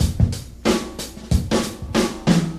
• 93 Bpm Drum Beat D Key.wav
Free drum groove - kick tuned to the D note.
93-bpm-drum-beat-d-key-Rmk.wav